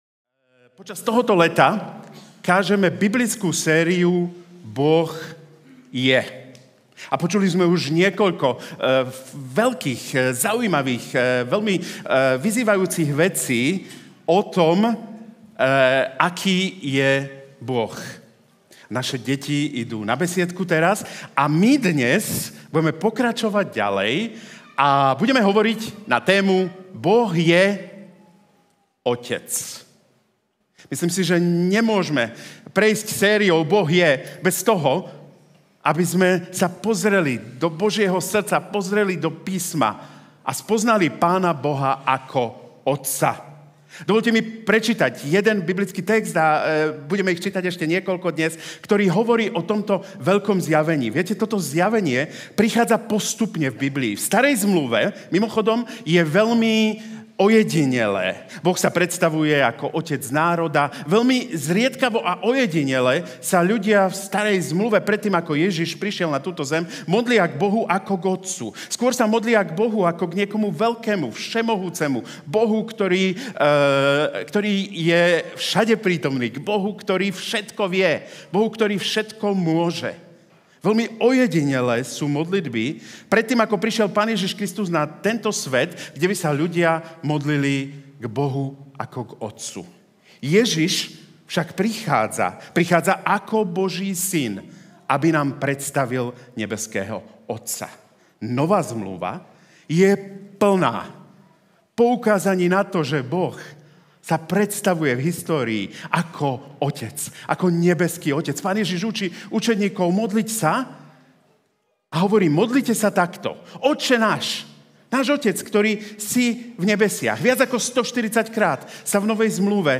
Boh je otec Kázeň týždňa Zo série kázní